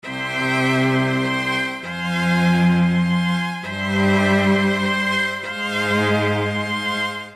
描述：字符串短语
Tag: 100 bpm Classical Loops Strings Loops 1.24 MB wav Key : Unknown